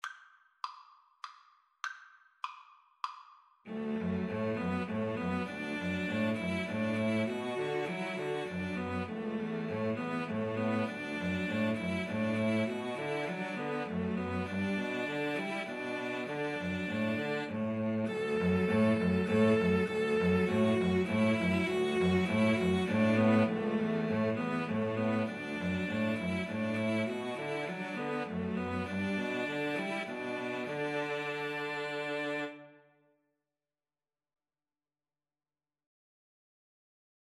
Play (or use space bar on your keyboard) Pause Music Playalong - Player 1 Accompaniment Playalong - Player 3 Accompaniment reset tempo print settings full screen
Cello 1Cello 2Cello 3
"Arirang" is a Korean folk song, often considered as the unofficial national anthem of Korea.
3/4 (View more 3/4 Music)
D major (Sounding Pitch) (View more D major Music for Cello Trio )
Moderato